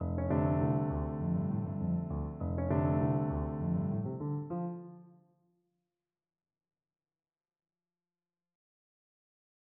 드뷔시는 피아노 전주곡 ''라비느 장군 - 괴짜'' (1913)에서 6화음을 사용했다. 이 곡은 1900년대 초반의 대중적인 기법인 케이크워크와 래그타임을 연상시킨다.[20]